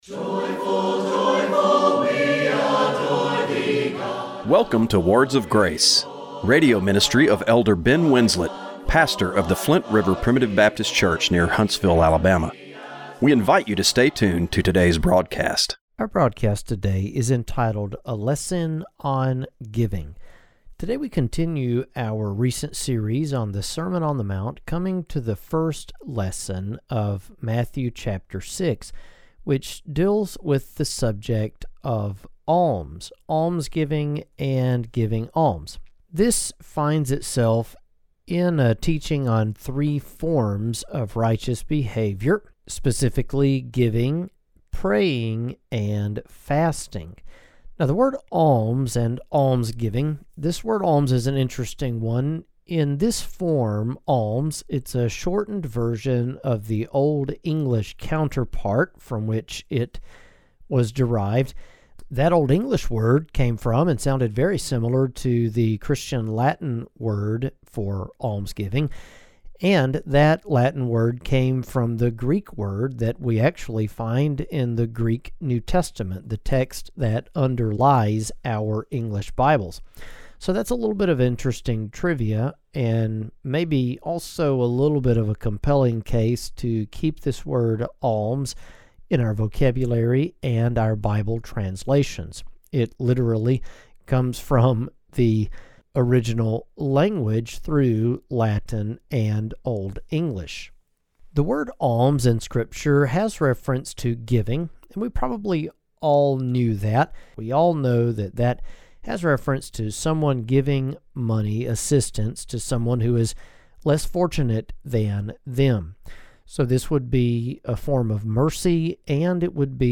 Radio broadcast for July 13, 2025.